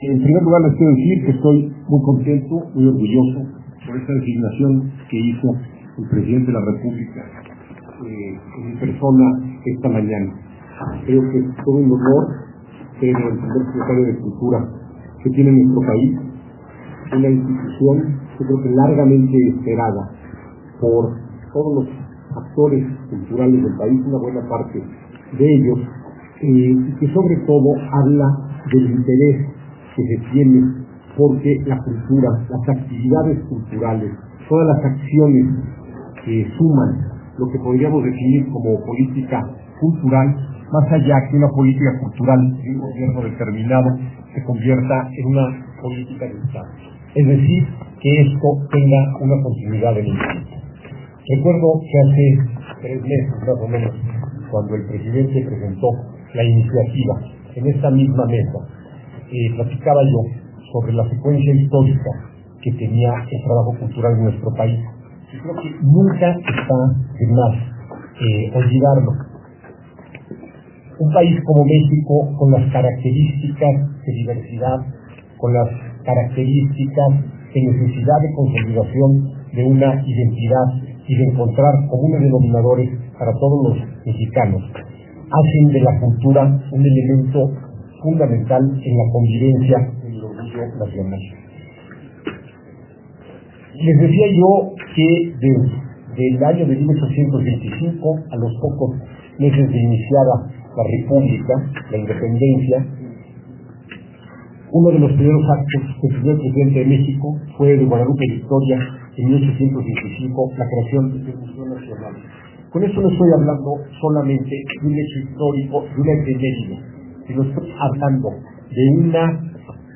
Rafael Tovar y de Teresa sostuvo este lunes 21 de diciembre su primera reunión con los medios de comunicación.